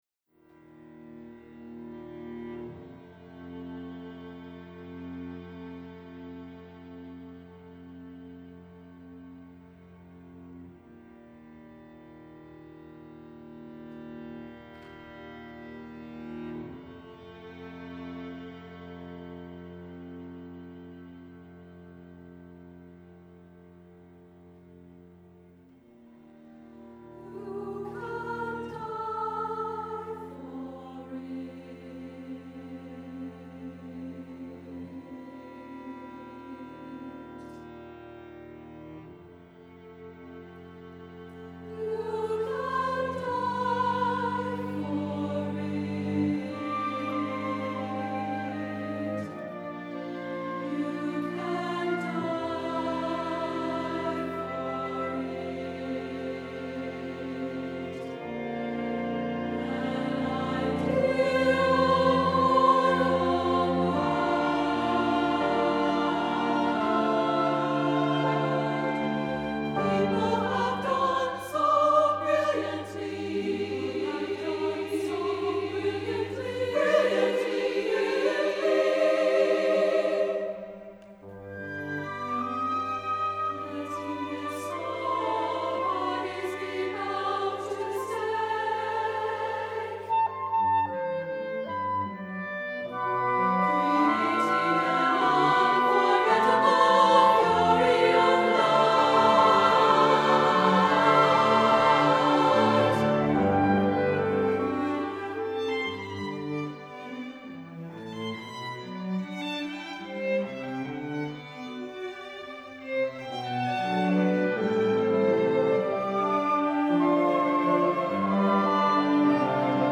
SSA(A) with instrumental chamber ensemble